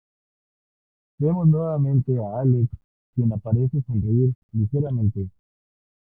li‧ge‧ra‧men‧te
/liˌxeɾaˈmente/